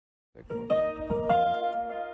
ÖBB Ansage